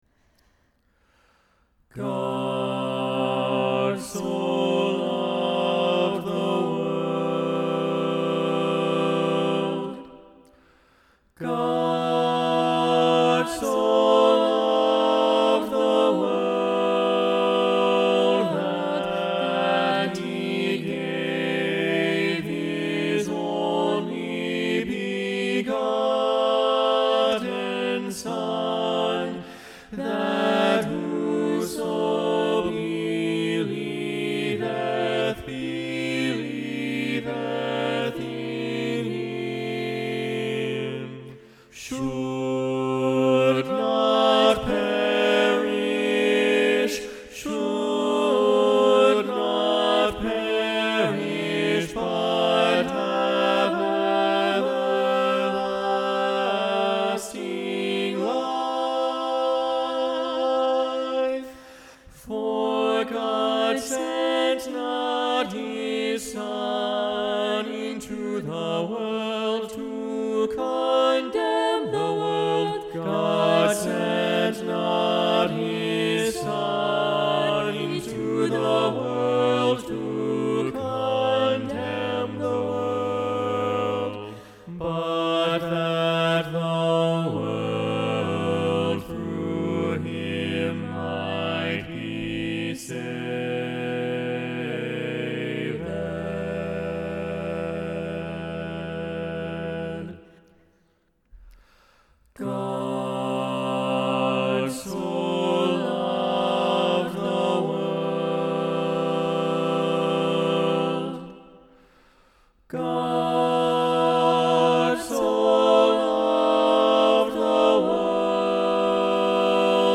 God so Loved the World SATB – Soprano Muted – John StainerDownload